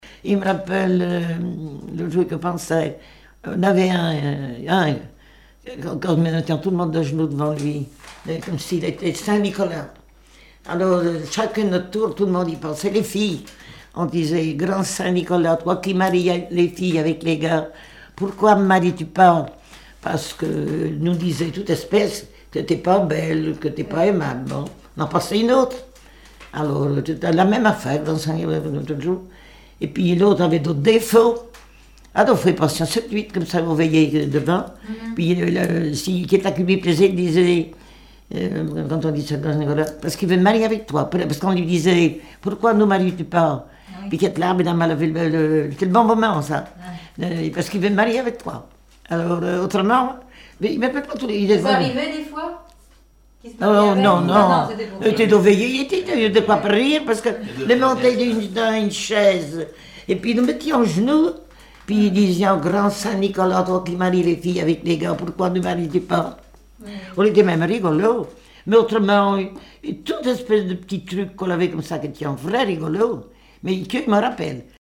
Répertoire de chansons populaires et traditionnelles
Catégorie Témoignage